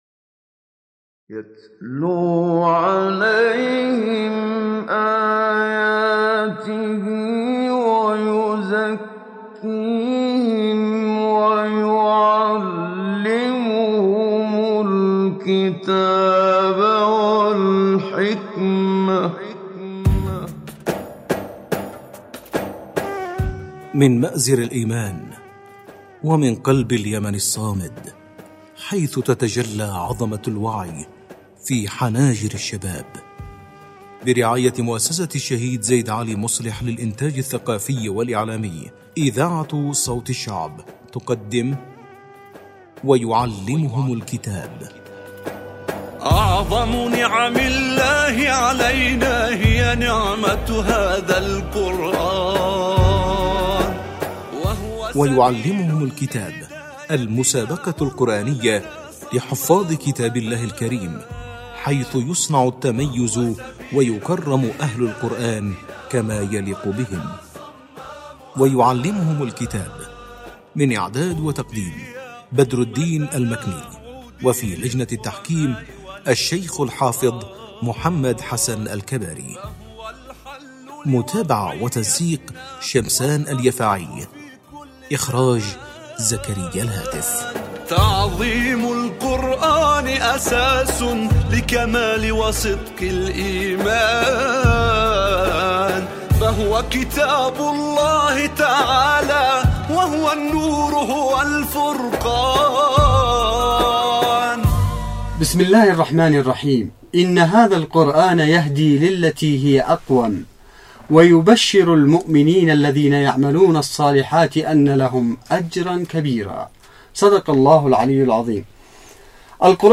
مسابقة_القرآن_الكريم_ويعلمهم_الكتاب_9.mp3